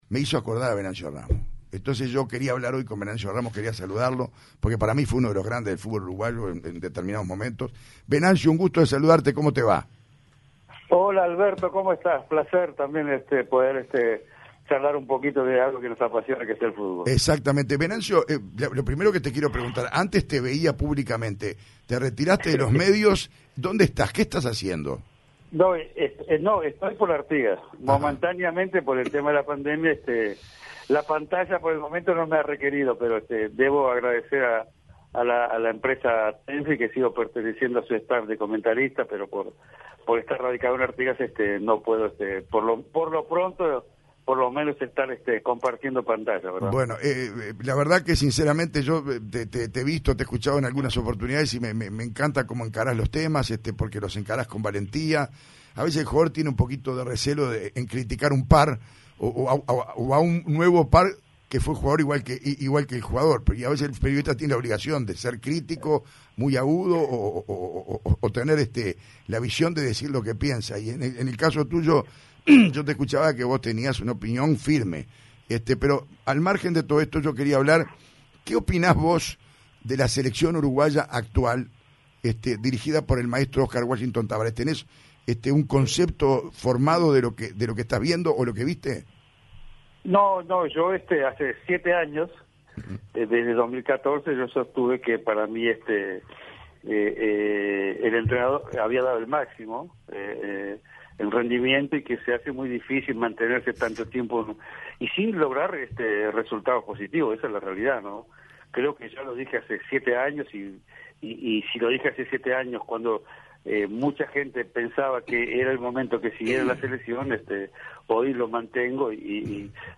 El exfutbolista Venancio Ramos habló este mediodía en La Oral Deportiva y brindó su parecer de la participación de Uruguay en la Copa América de Brasil 2021, tras ser eliminada por Colombia en cuartos de final del certamen.